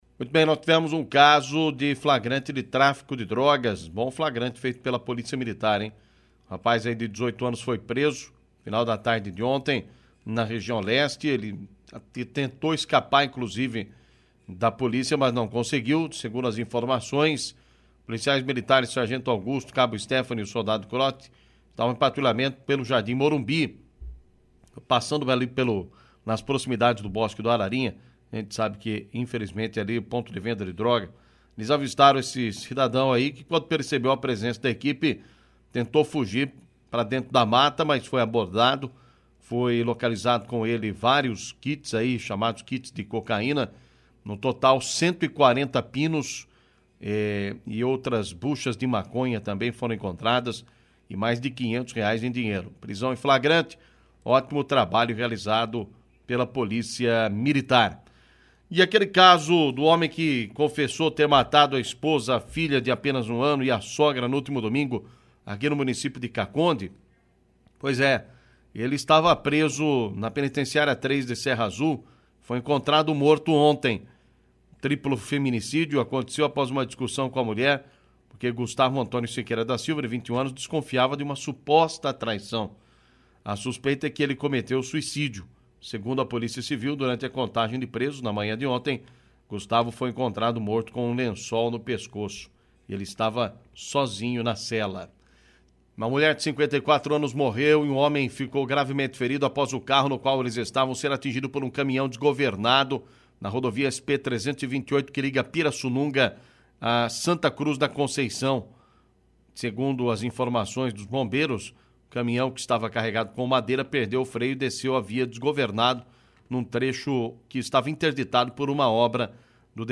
Destaque Polícia
Rádio Clube • 101,7 FM 🔴 AO VIVO